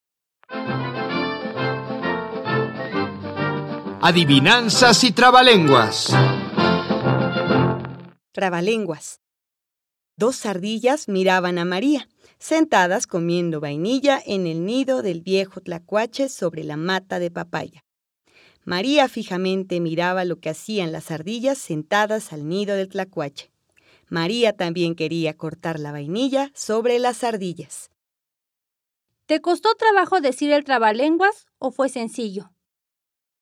Audio 15. Trabalenguas
122_Trabalenguas.mp3